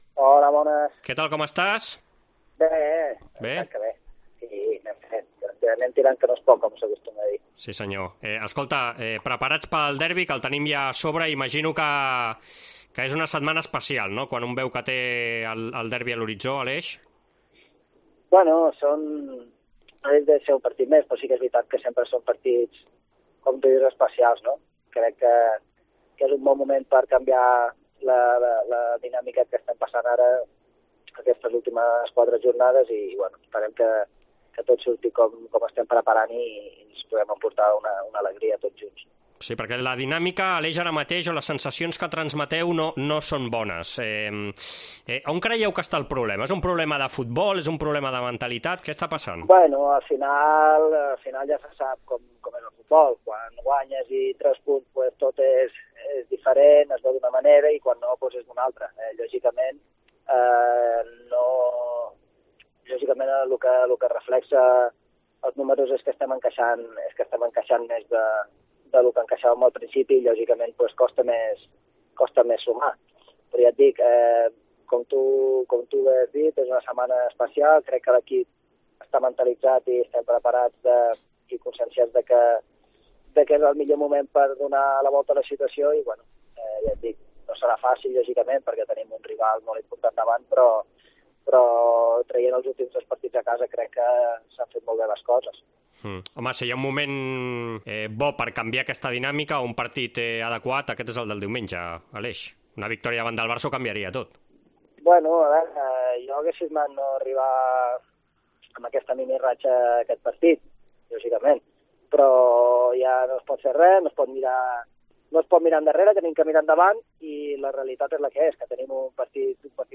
El jugador de l'Espanyol Aleix Vidal, amb passat blaugrana, ha parlat amb Esports COPE abans de tornar a enfrontar-se al seu exequip "són partits especials, és un bon moment per canviar la dinàmica de les últimes setmanes".